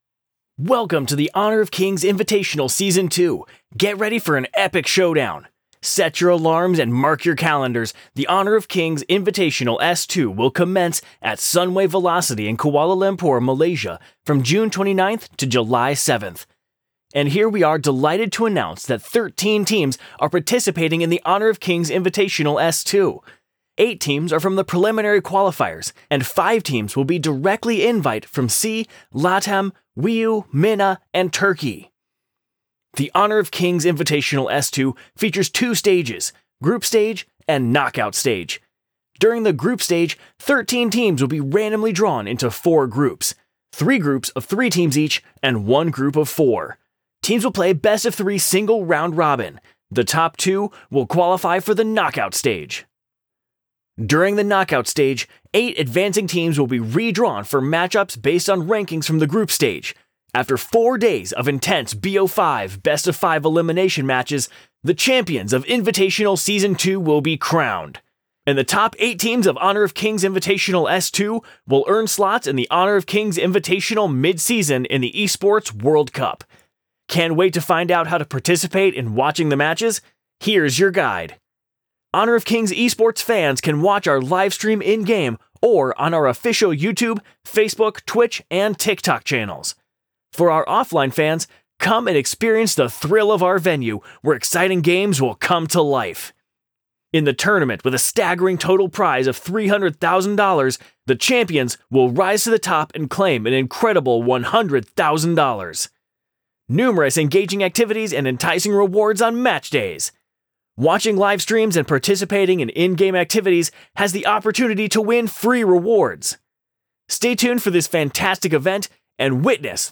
外籍英语男声（免费试音） ,莱恩外语录音/翻译